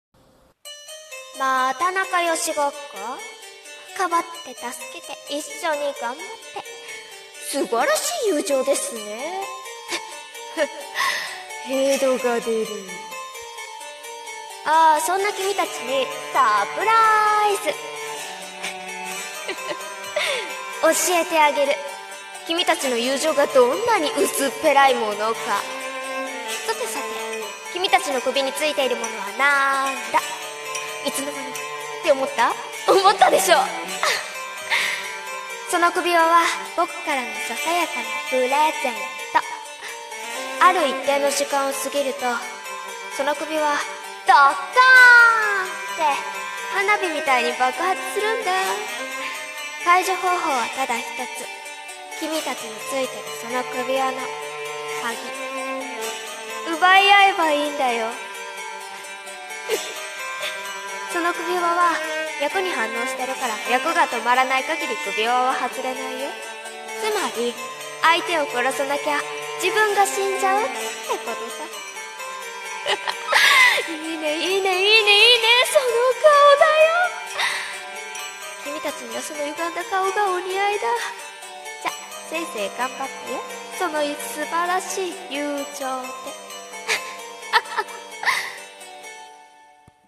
【一人声劇】教えてあげる【悪役】